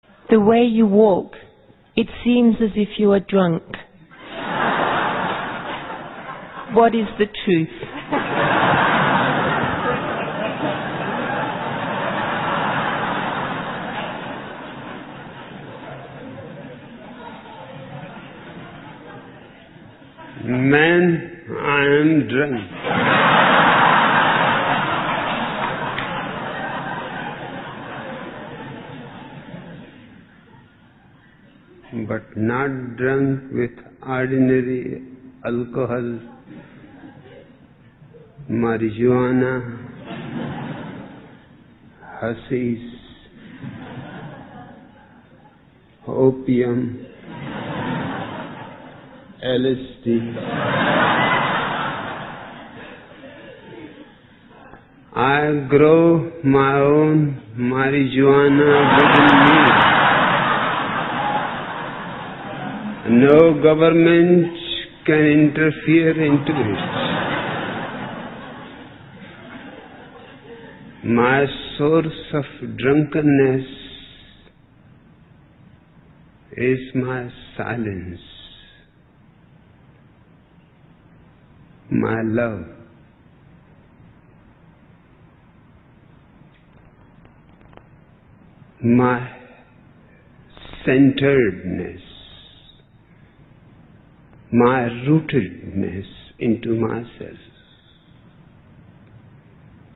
(Osho - Tao: The Pathless Path, vol.1 #1) Walking the Walk (a .pdf-file) The way you walk it seems as if you are drunk This question was put to Osho, listen to his answer (.mp3 audio, 2,28 MB, right-click and "save link as")